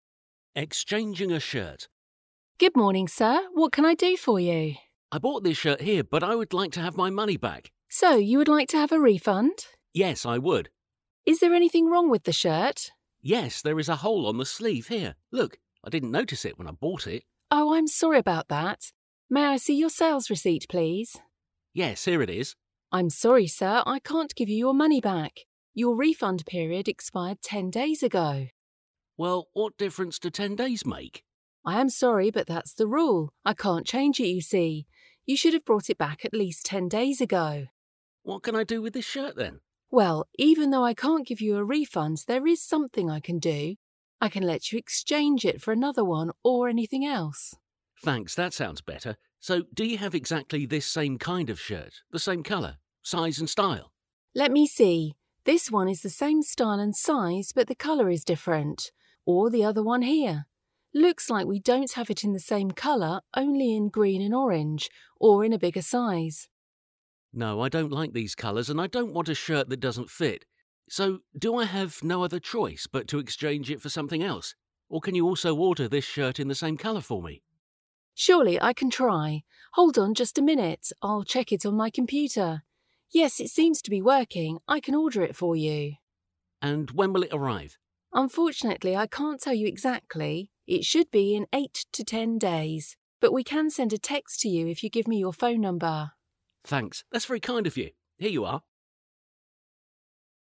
Listen to the dialogue, then read about it again and fill in the gaps with the information you have heard.